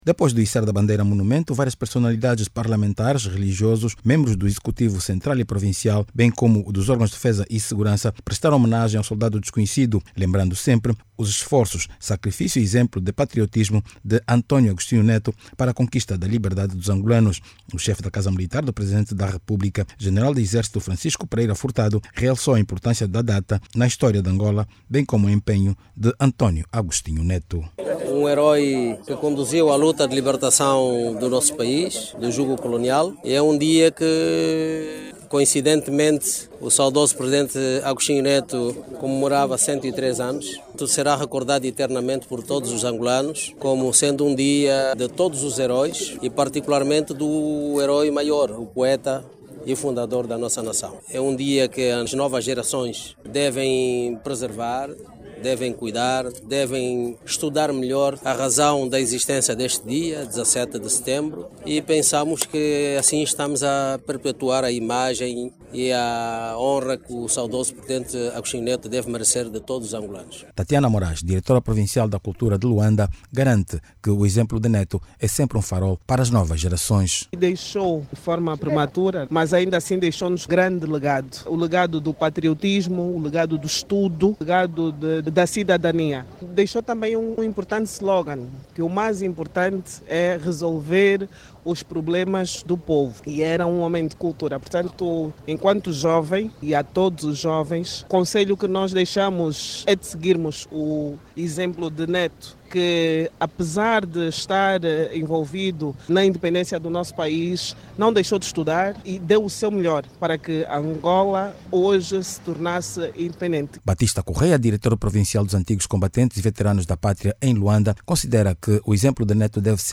Várias personalidades em Luanda, renderam homenagem ao herói nacional, com o içar da bandeira monumento.